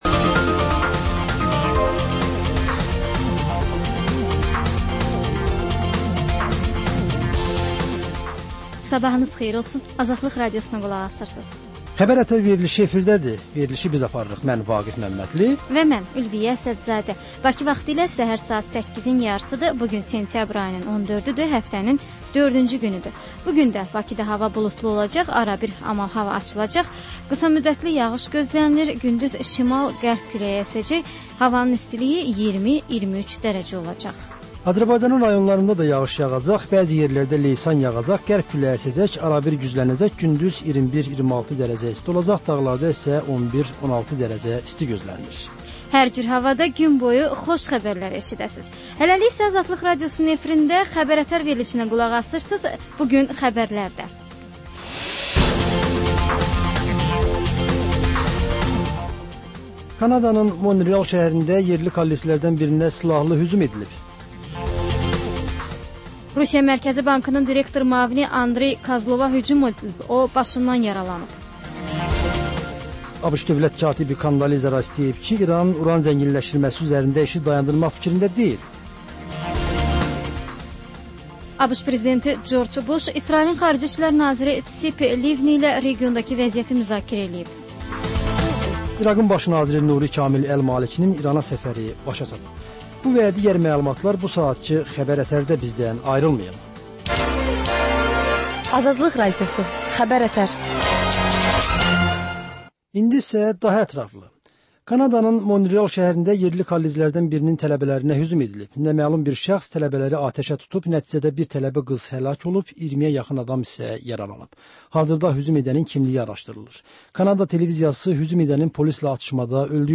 Səhər-səhər, Xəbər-ətərş Xəbərlər, reportajlar, müsahibələr